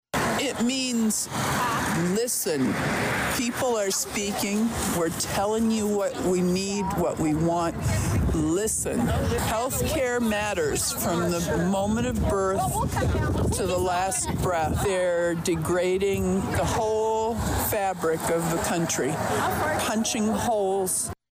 It began at 11 AM, on Main Street in front of VA Illiana Health Care; with a crowd of already of 70, and the numbers kept increasing during Saturday morning’s HANDS OFF rally in Danville; one of many across the country that were put together to protest the actions of Elon Musk and the Trump Administration.